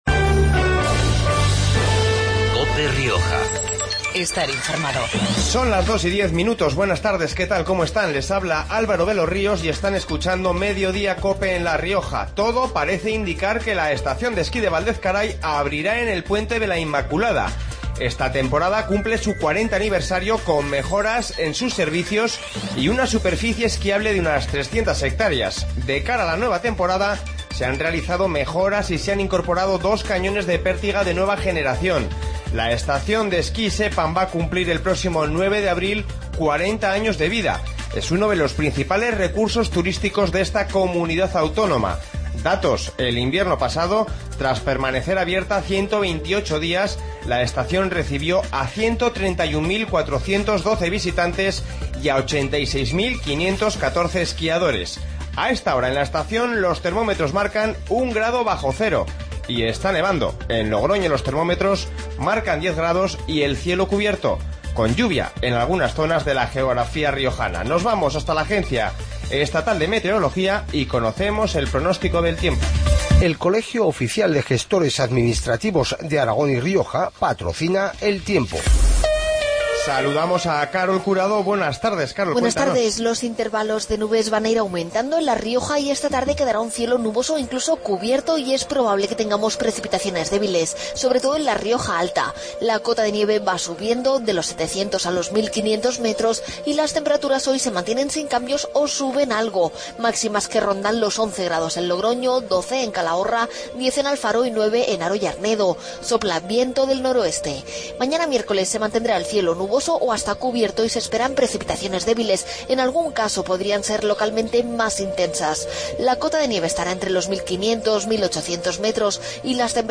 Informativo Mediodia en La Rioja 24-11-15